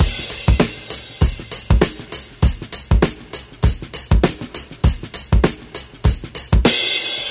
• 99 Bpm Breakbeat Sample C Key.wav
Free breakbeat - kick tuned to the C note.
99-bpm-breakbeat-sample-c-key-yii.wav